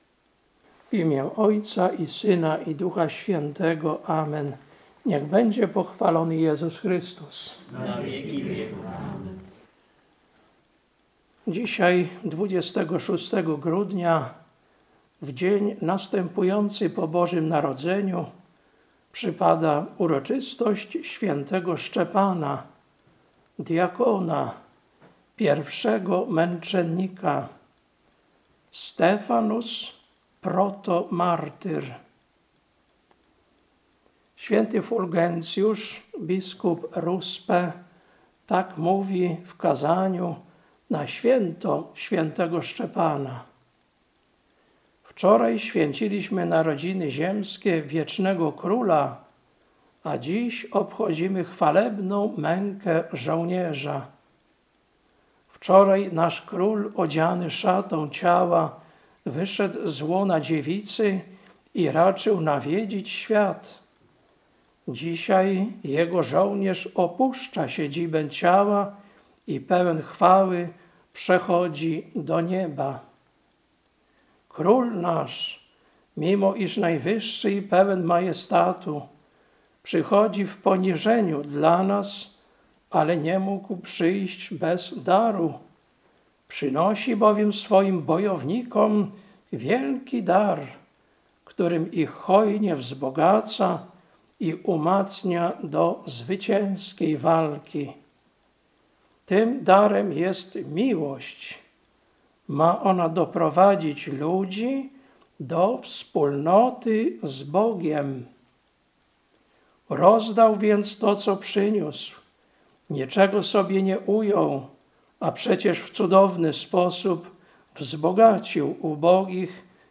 Kazanie na Pasterkę, 25.12.2025 Lekcja: Tt 2, 11-15 Ewangelia: Łk 2, 1–14